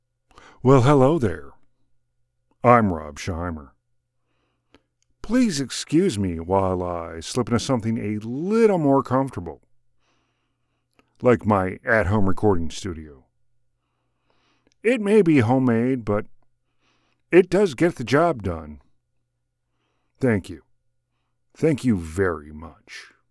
Deep, Male Voice.
Midwest.
Middle Aged